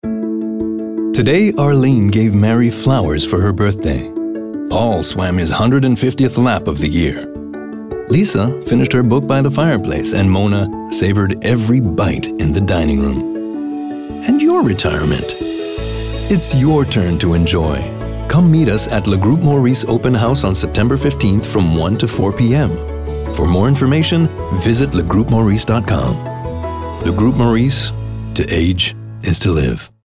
Commercial (Groupe Maurice) - EN